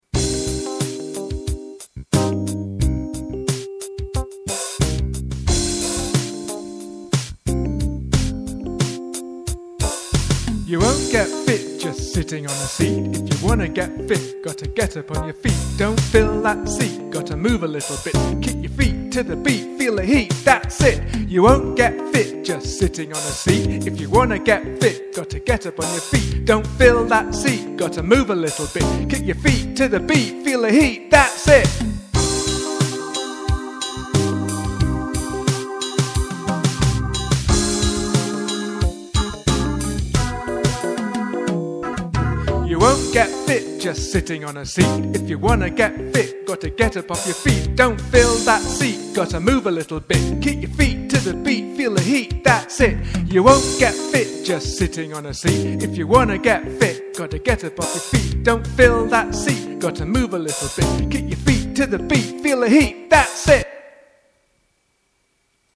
Keep Fit Rap straight_0.mp3